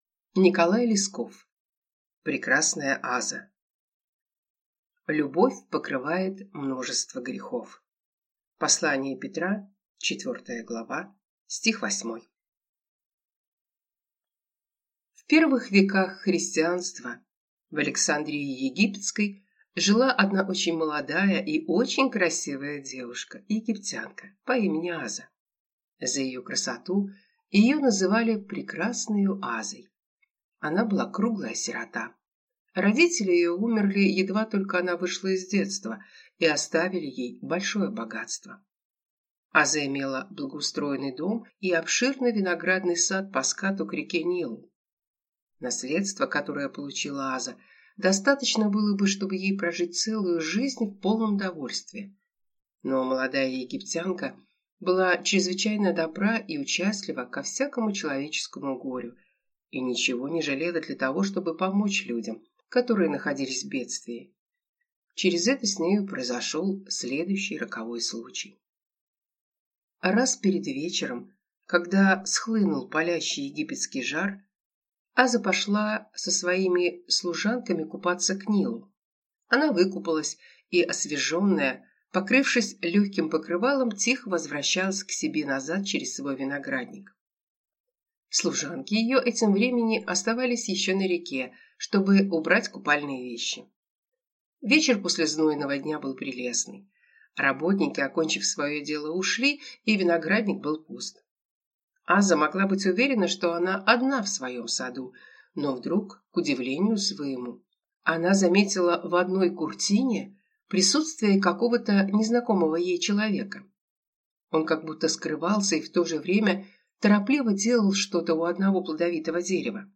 Аудиокнига Прекрасная Аза | Библиотека аудиокниг